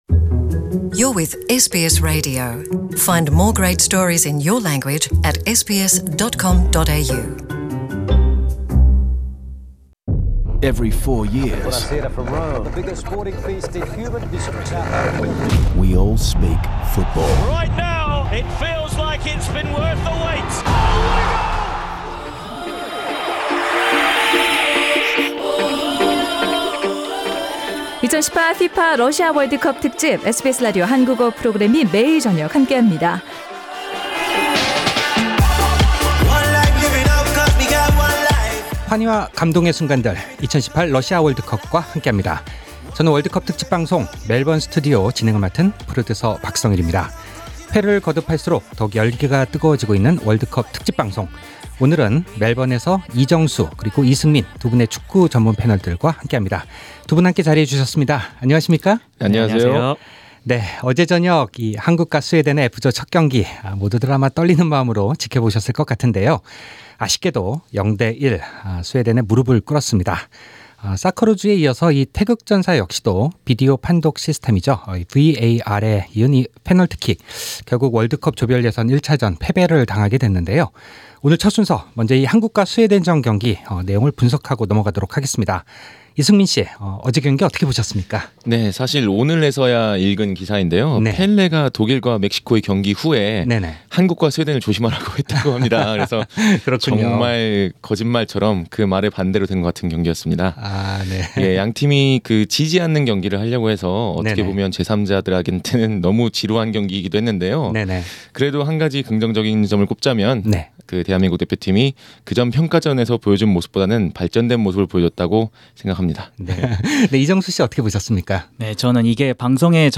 The World Cup panel of two in Melbourne